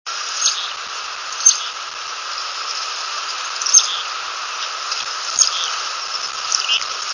These unusual contact calls were recorded days after a large blizzard.  A small family of 5 or 6 Chickadees was feeding in mature pines growing in the dunes covered with 6 inches of snow.   The calls carried quite well against the loud rumble from the rolling waves in the Sound about 500 feet away. Notice the much lower pitch of the last call.
chickadees687.wav